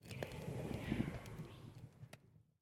Minecraft Version Minecraft Version 1.21.5 Latest Release | Latest Snapshot 1.21.5 / assets / minecraft / sounds / block / trial_spawner / ambient1.ogg Compare With Compare With Latest Release | Latest Snapshot